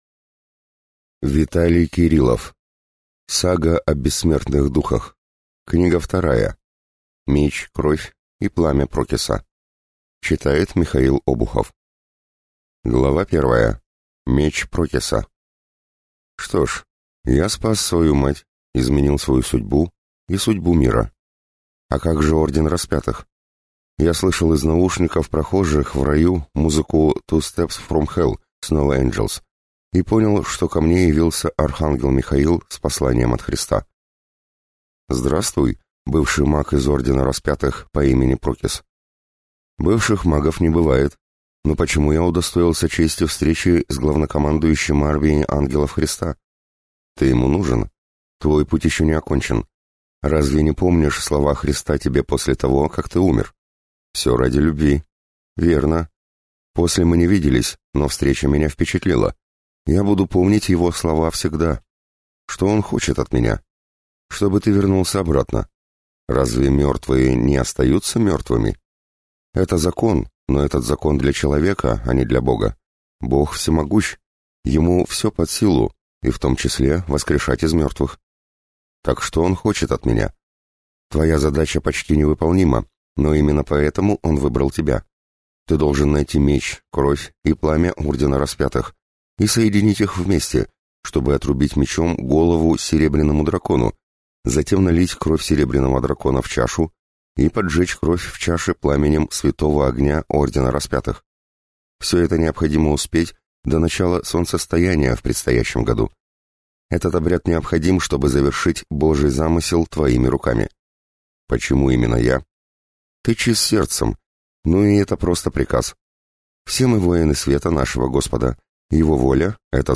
Аудиокнига Сага о бессмертных духах. Книга 2. Меч, кровь и пламя Прокиса | Библиотека аудиокниг